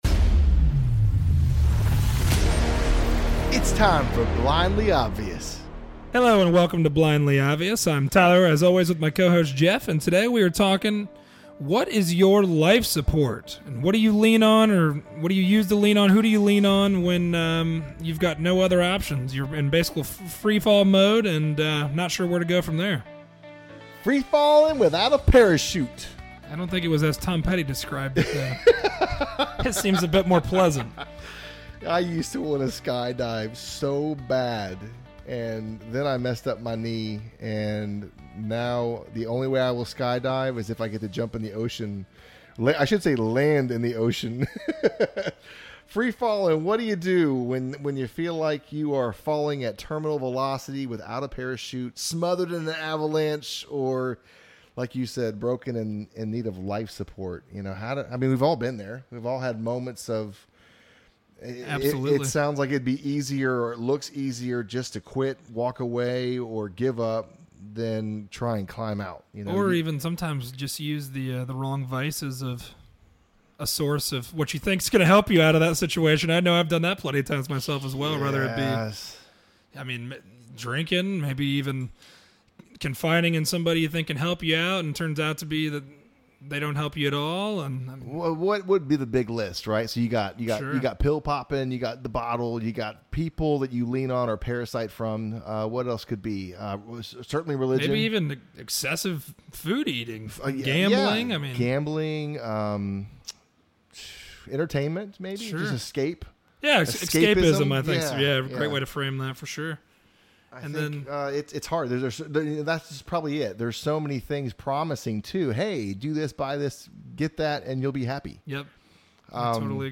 A conversation about who and/or what it is we lean on in situations where life has us feeling like we’re in a free fall of negativity.